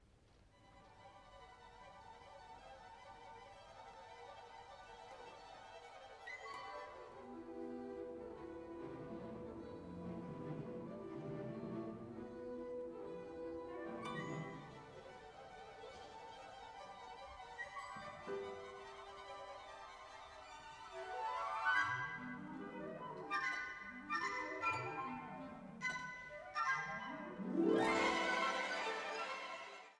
repräsentative Live-Aufnahmen
Ostinato